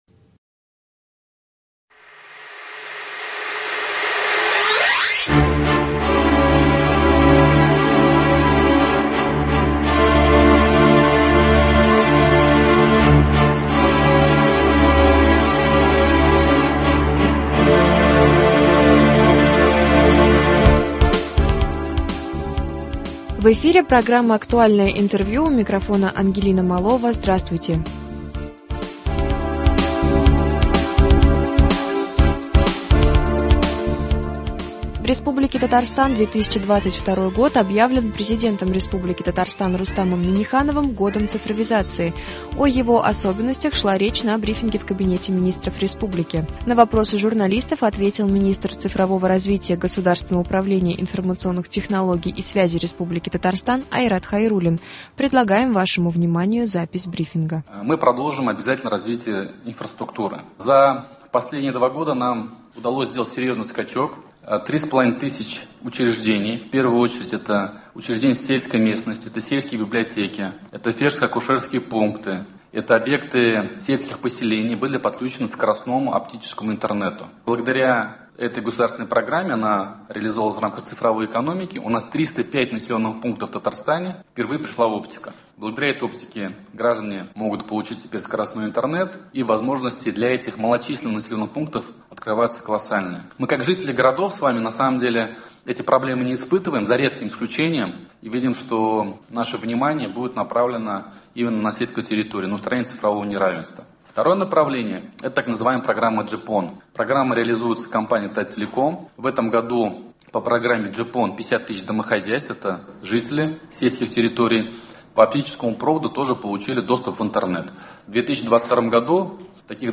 Актуальное интервью (19.01.22)